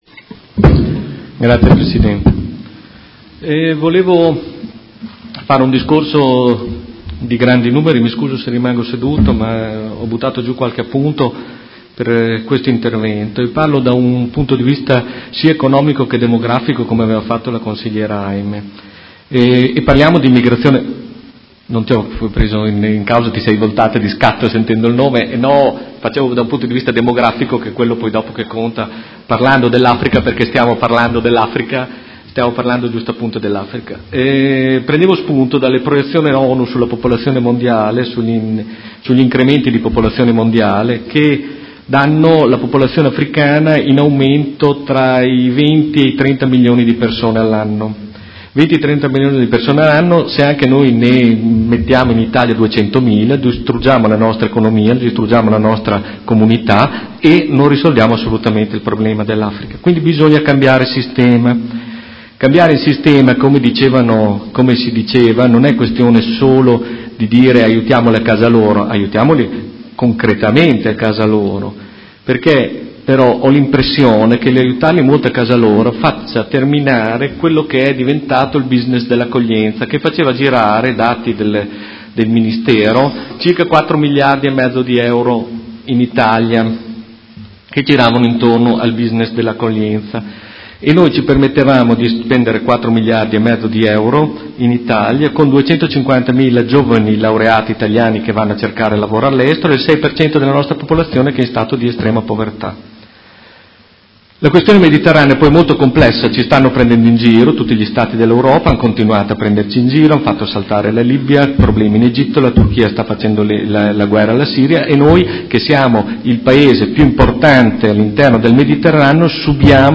Stefano Prampolini — Sito Audio Consiglio Comunale